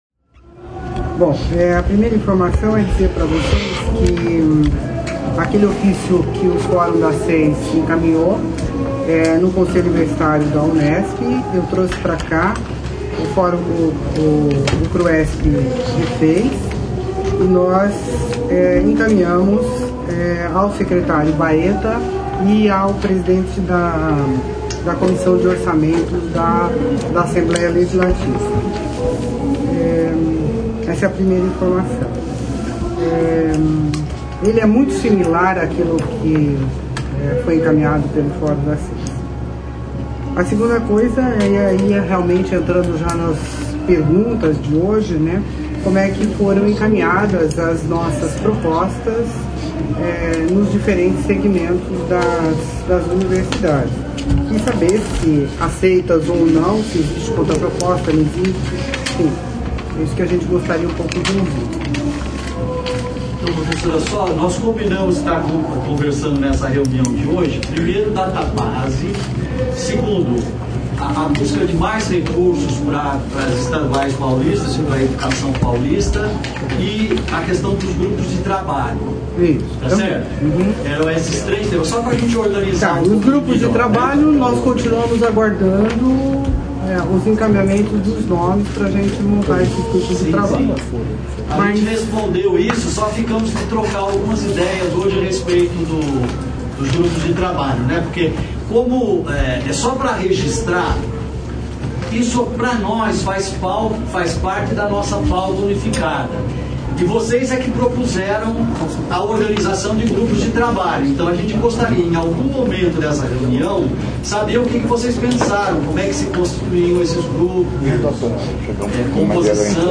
Escute o registro da reunião de negociação da terça-feira, 9 de setembro, na sede do Cruesp.